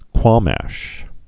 (kwŏmăsh)